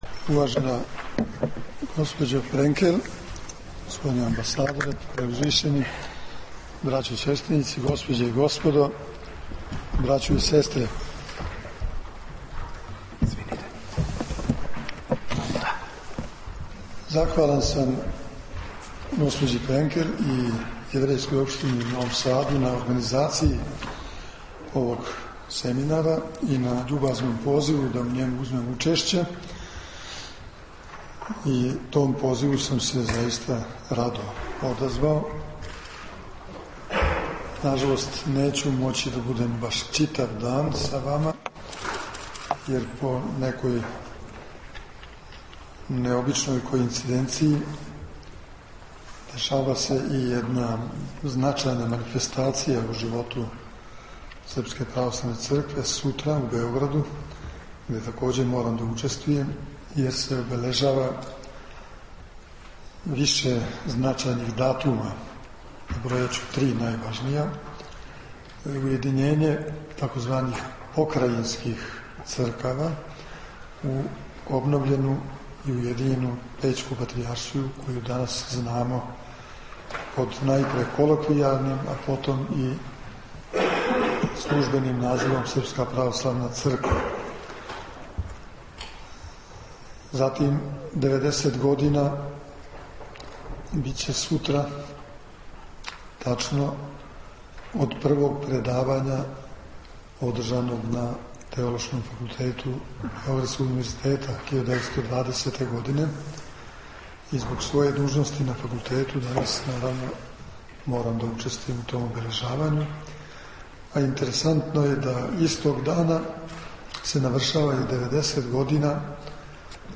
Преподневни део семинара завршен је предавањем Његовог Преосвештенства Епископа бачког Господина др Иринеја на тему Холокауст са становишта Православне Цркве.